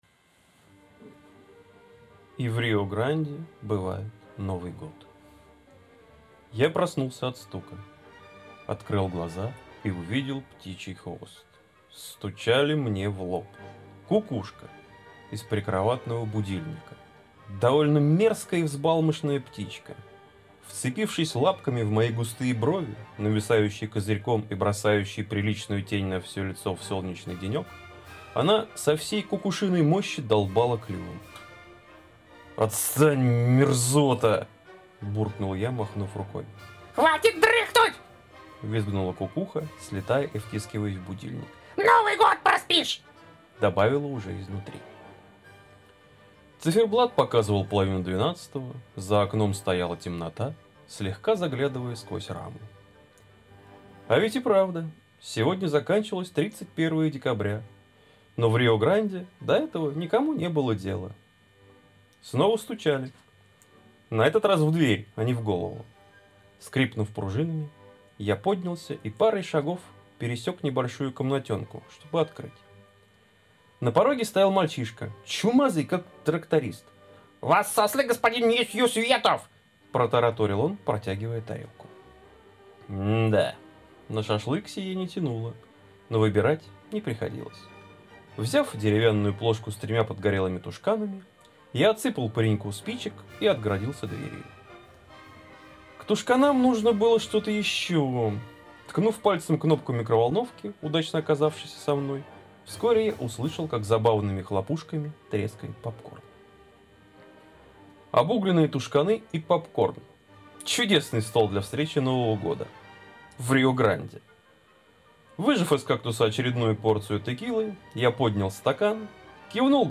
некий диссонанс между фоновой залихватской мелодией и размеренным голосом чтеца сообщает особый шарм произведю  :cool:
Хорошее, приятное прочтение)
:D чтец был ленив, а венгерский танец стремителен :D